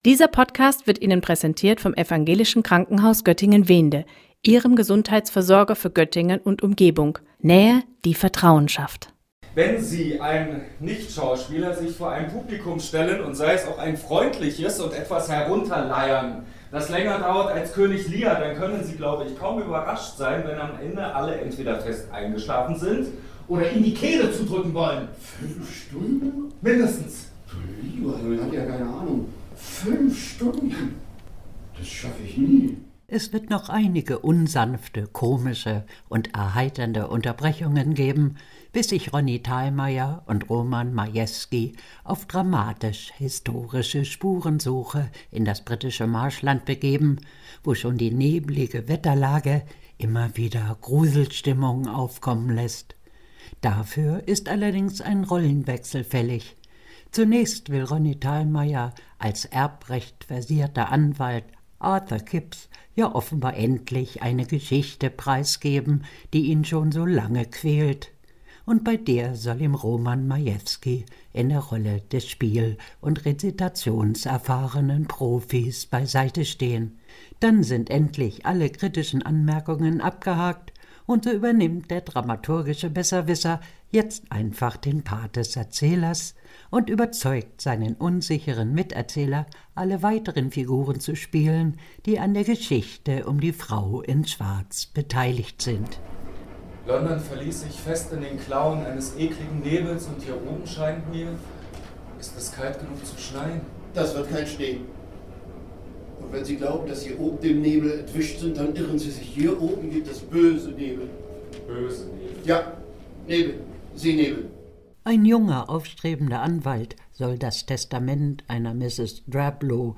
O-Ton 1, Einspieler, „Die Frau in Schwarz“, 22 Sekunden